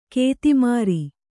♪ kēti māri